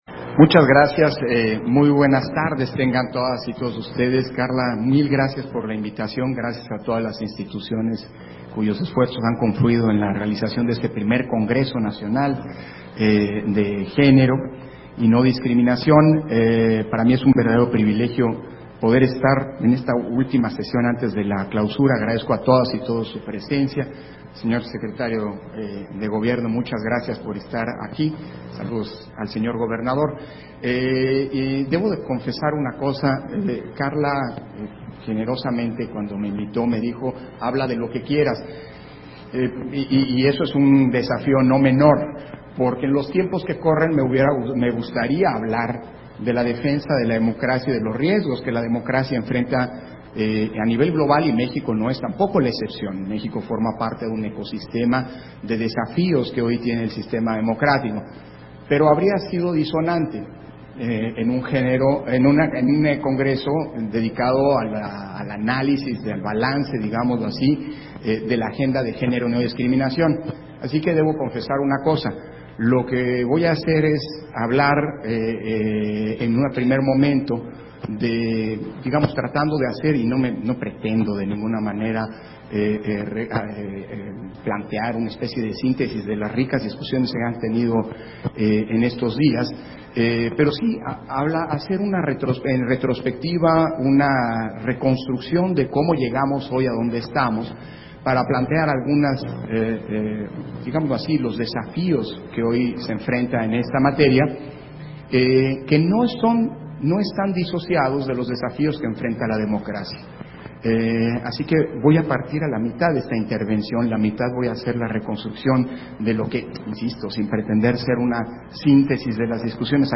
Intervención de Lorenzo Córdova, en la conferencia: Las autoridades electorales nacionales y su papel ante la agenda democrática pendiente